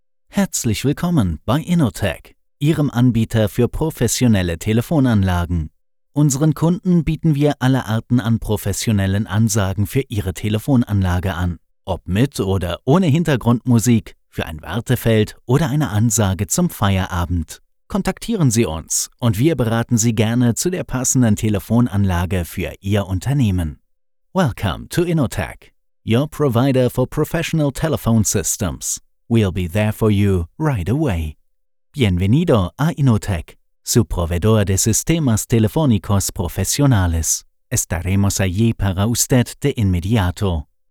Ansagen
Sprecher männlich 2